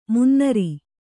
♪ munnari